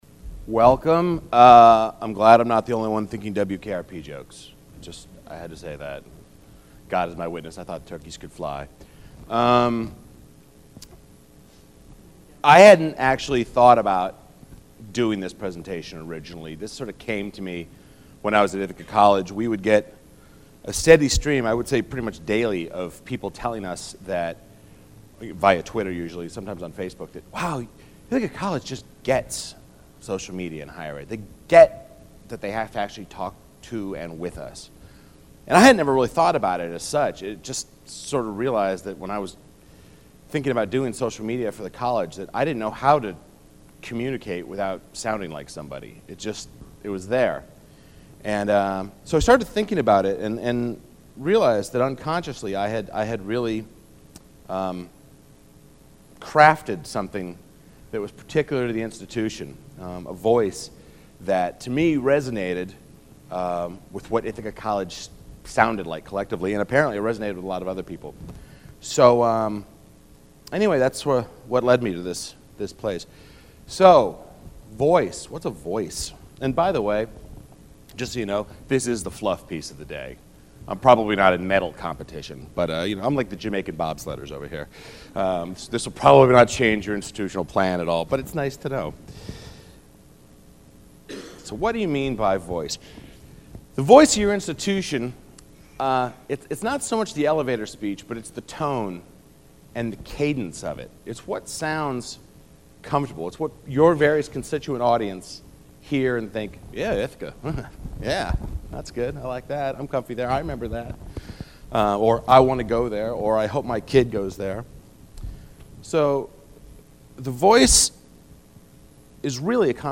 Continental Ballroom, Mezzanine Level